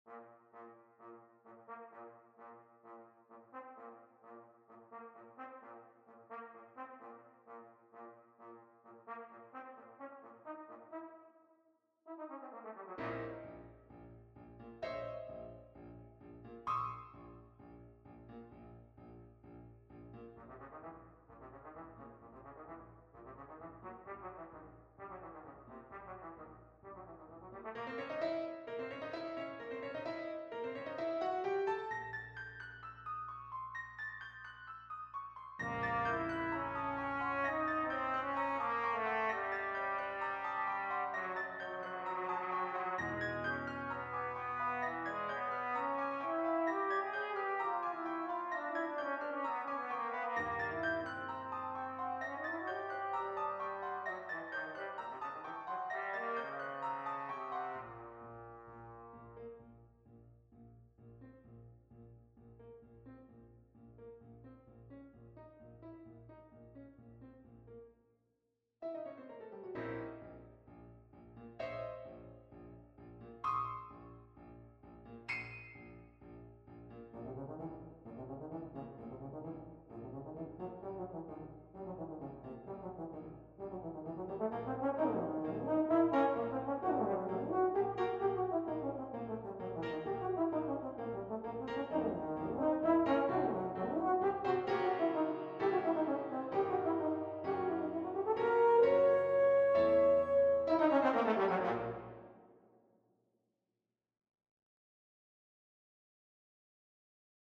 I love octatonic scales!